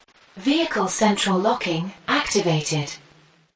Tesla Lock Sound English British Woman
English female voice saying
with a british accent
(This is a lofi preview version. The downloadable version will be in full quality)
JM_Tesla-Lock_English-UK_Woman_Watermark.mp3